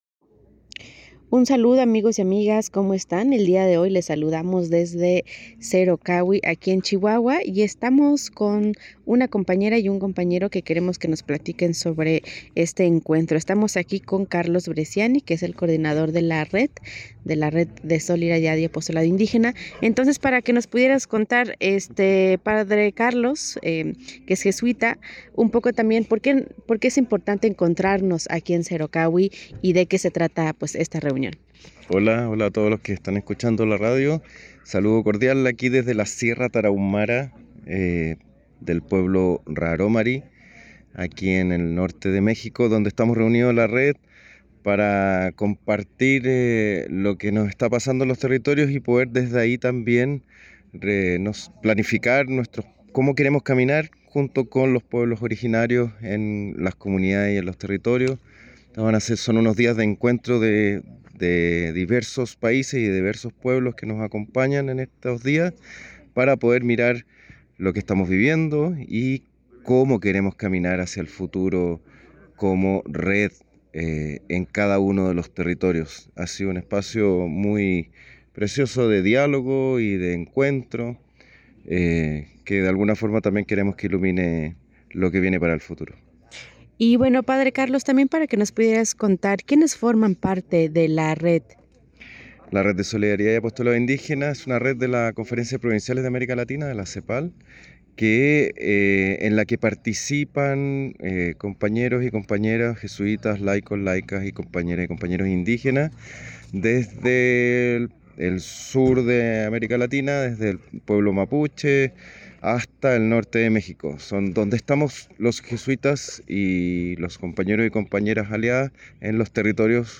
Reporte desde Cerocahui en Chihuahua